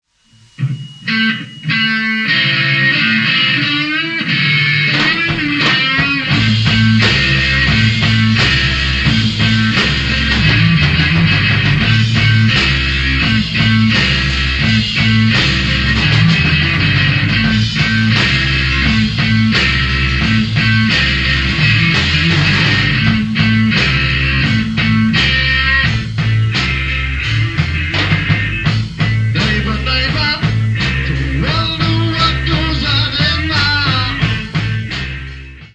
Heavy Metal Rock.